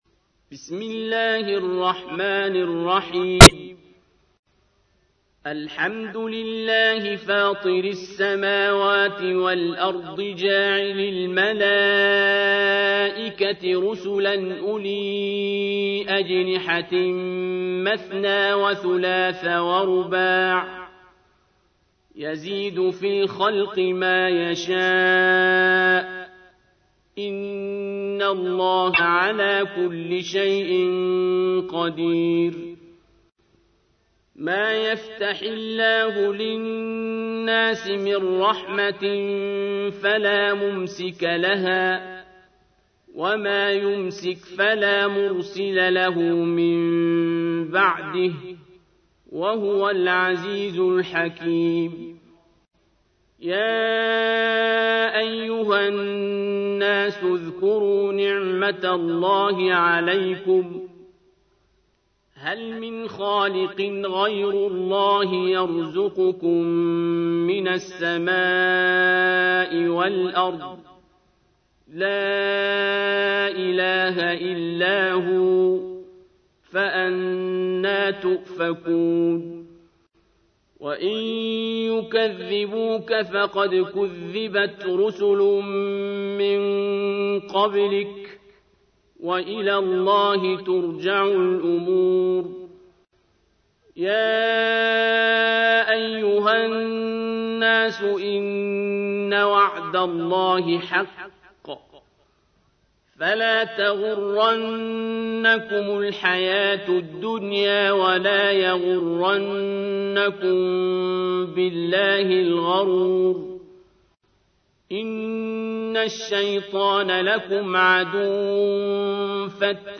تحميل : 35. سورة فاطر / القارئ عبد الباسط عبد الصمد / القرآن الكريم / موقع يا حسين